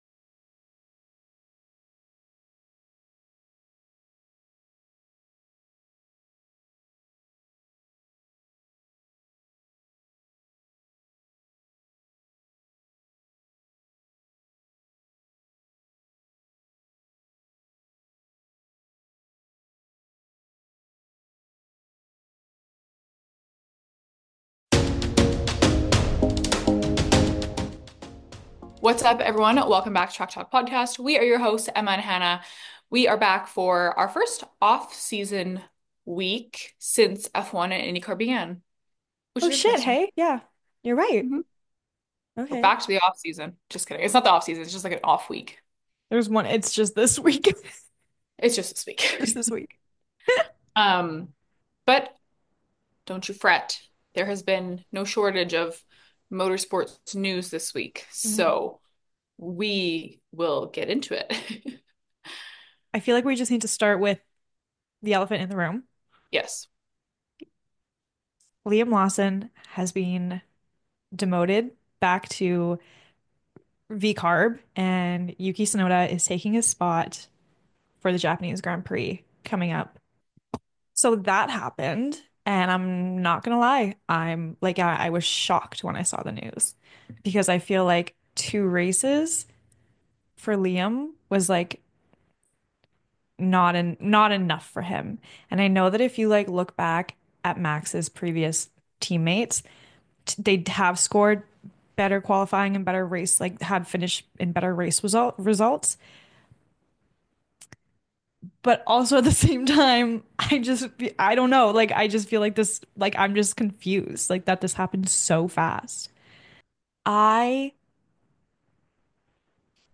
Ford Performance: A Conversation with CEO Jim Farley – Track Talk Podcast – Podcast – Podtail